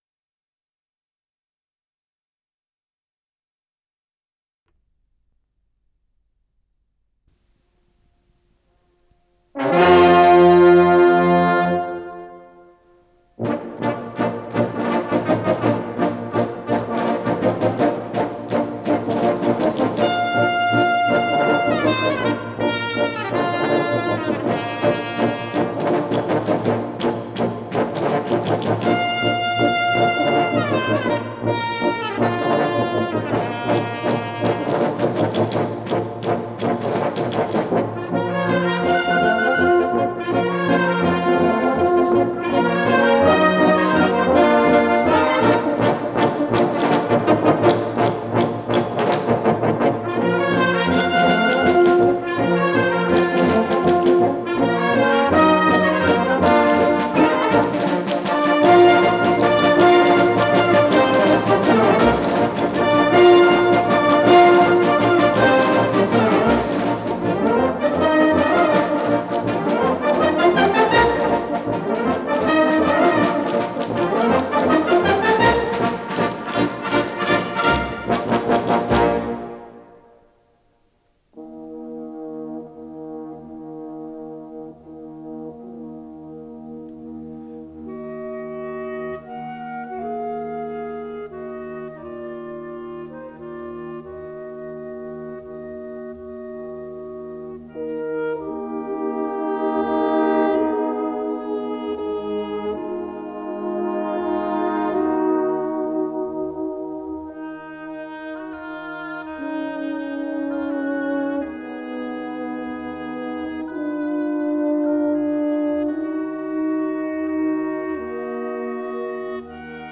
с профессиональной магнитной ленты
ИсполнителиДуховой оркестр штаба Краснознамённого Киевского военного округа
ВариантДубль моно